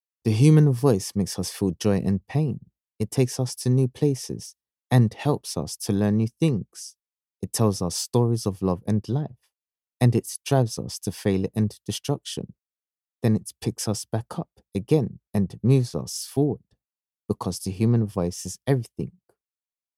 Never any Artificial Voices used, unlike other sites.
English (Caribbean)
Adult (30-50) | Yng Adult (18-29)